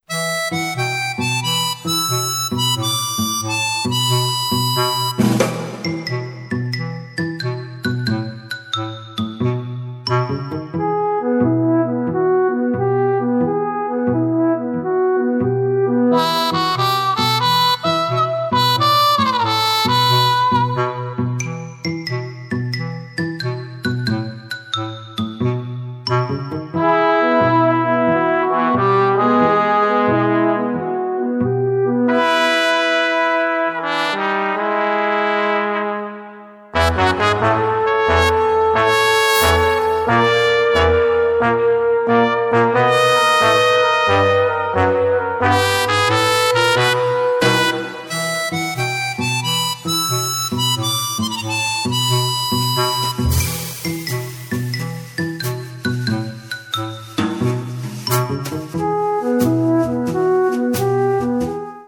xilófono
trompeta
trompa
armónica
batería
oboe
saxo